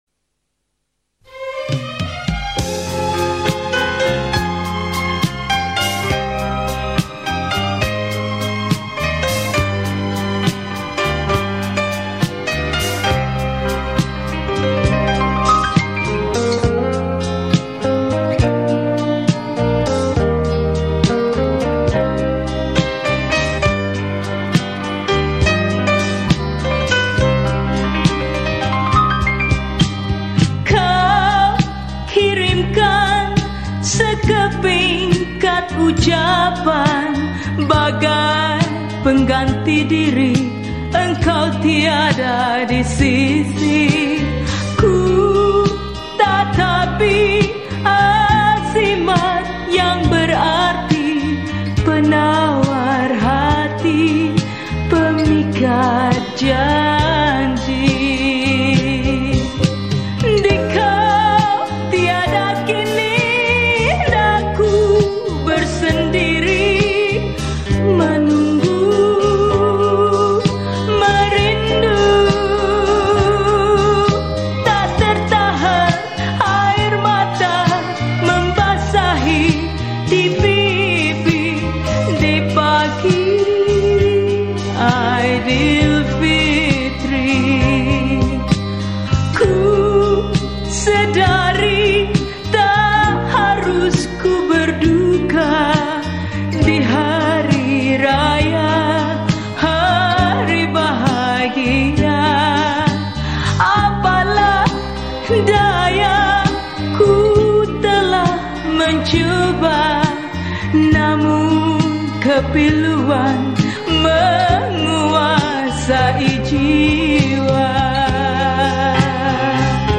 Genre: Raya.